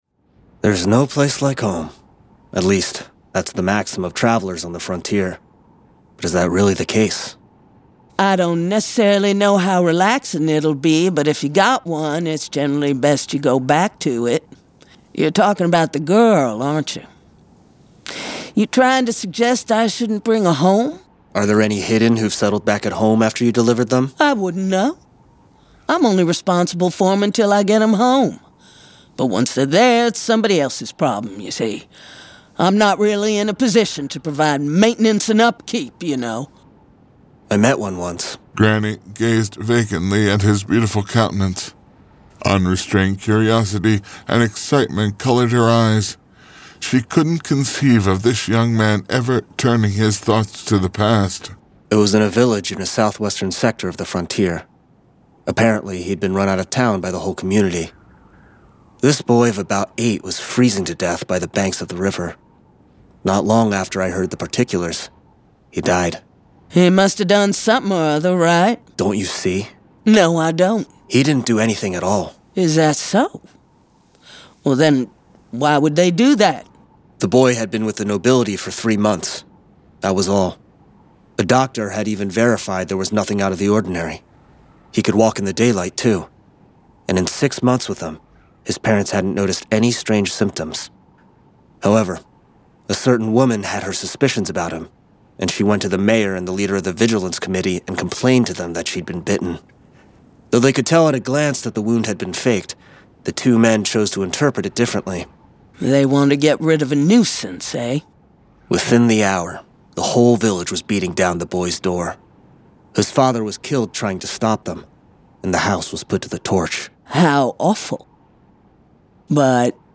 Full Cast. Cinematic Music. Sound Effects.
[Dramatized Adaptation]
Genre: Fantasy
Adapted from the novel and produced with a full cast of actors, immersive sound effects and cinematic music!